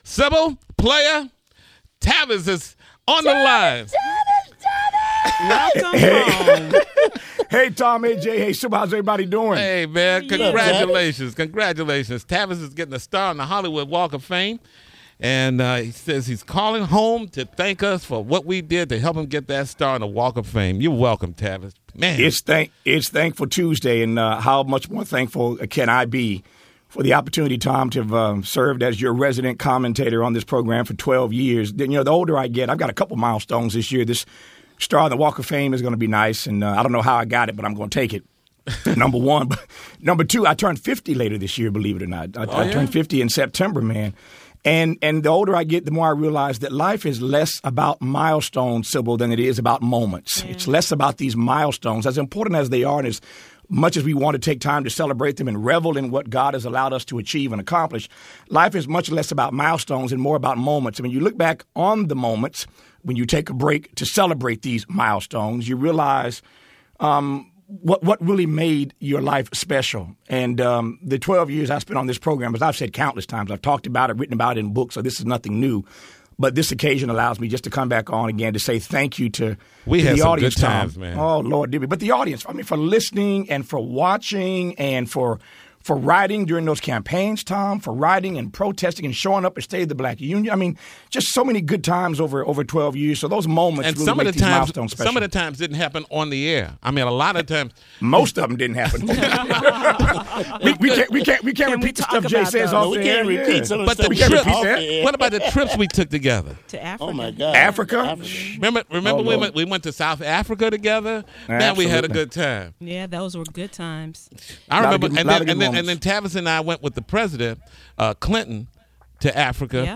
The author, radio and TV host and activist will get his own star on the Hollywood Walk of Fame. Smiley wanted to share his gratitude with the Tom Joyner Morning Show because he says the 12 years he spent on the show helped him reach that peak.
Tom remembers going to Africa with Smiley, especially one trip where Joyner sat between President Clinton and April Ryan, whom he says talked the entire 17 hours the trip took.